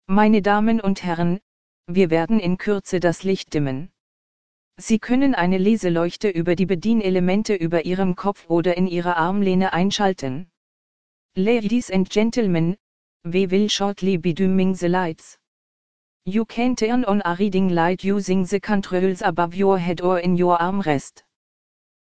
CabinDimTakeoff.ogg